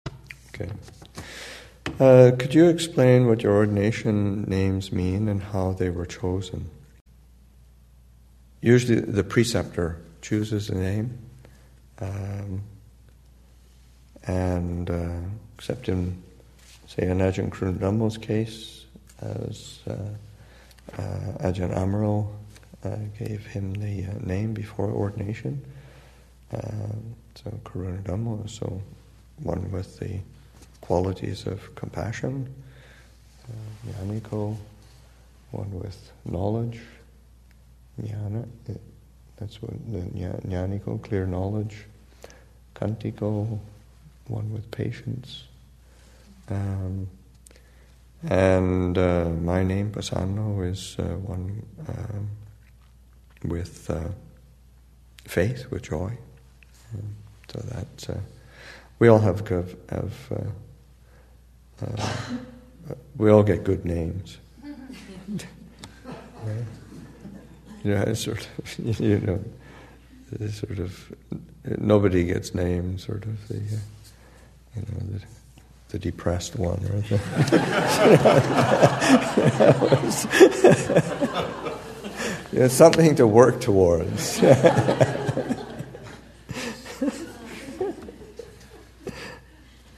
2015 Thanksgiving Monastic Retreat, Session 8 – Nov. 28, 2015